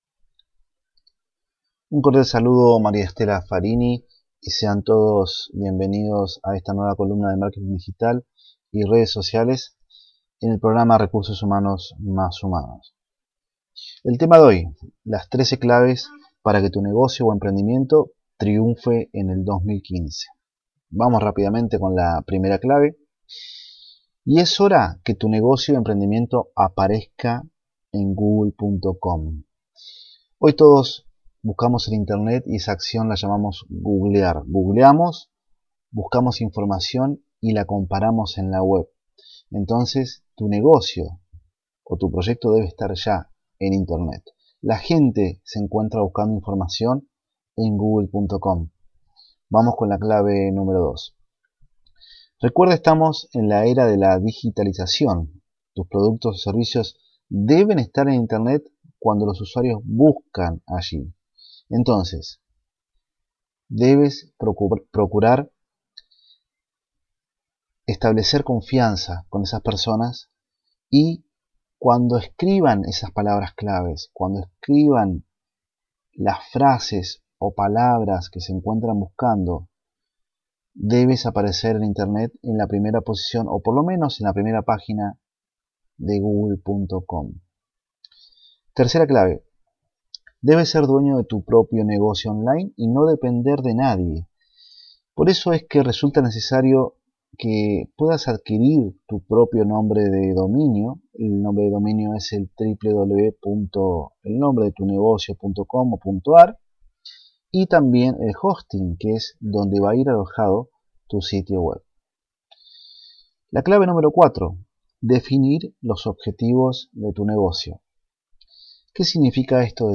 la última columna radial que realice sobre Marketing Digital y Redes Sociales para el programa Recursos Humanos mas Humanos